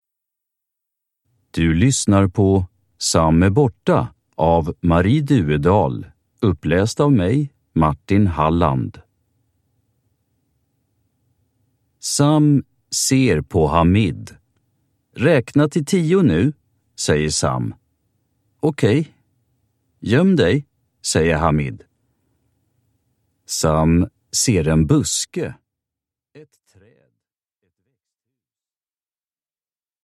Sam är borta – Ljudbok